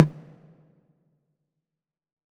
6CONGA SLP.wav